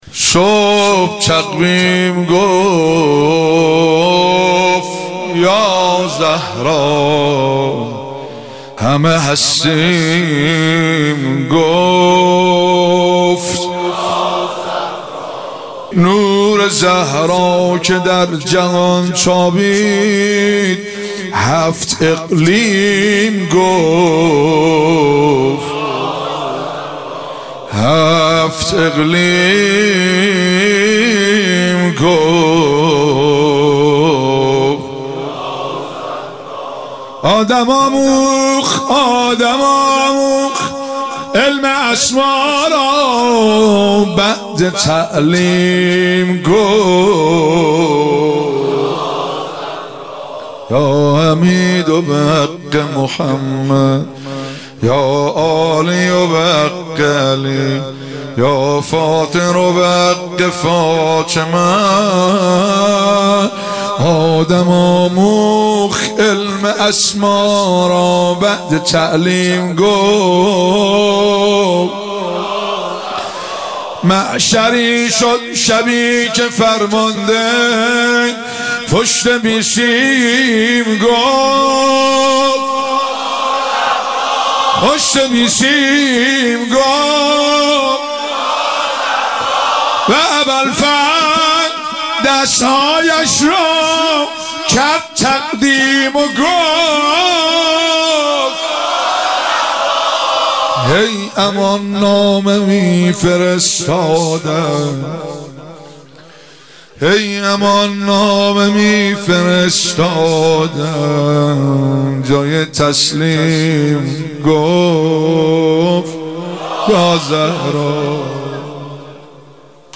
11 اسفند 95 - هيئت فاطميون - مناجات - صبح تقویم گفت یا زهرا